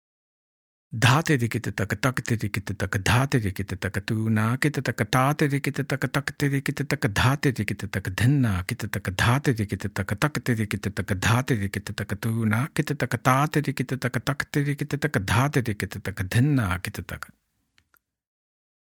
2x Speed – Spoken